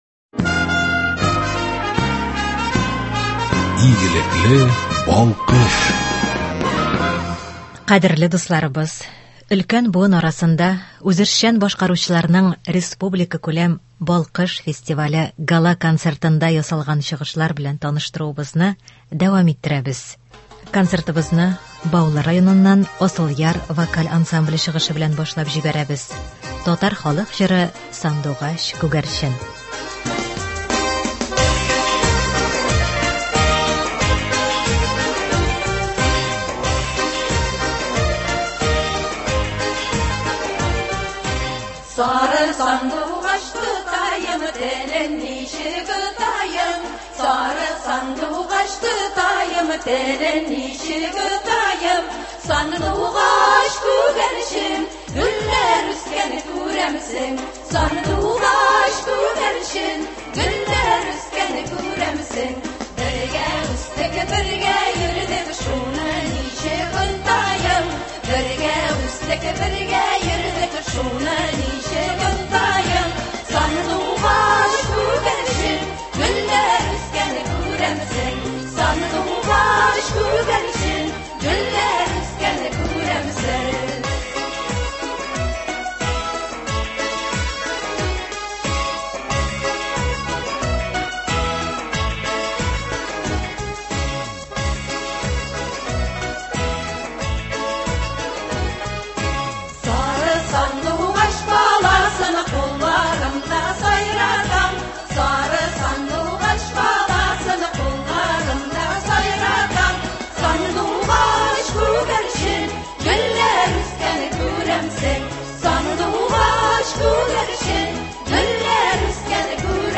Концерт.
Кадерле дусларыбыз, өлкән буын арасында үзешчән башкаручыларның республикакүләм «Балкыш» фестивале Гала-концертында ясалган чыгышлар белән таныштыруыбызны дәвам иттерәбез.
Һәм анда фестивальнең зона этапларында җиңеп чыккан ветераннарыбыз катнашты.